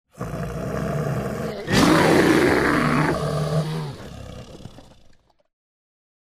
Звуки медведей
На этой странице собраны разнообразные звуки, издаваемые медведями: от грозного рычания взрослых особей до любопытных звуков медвежат.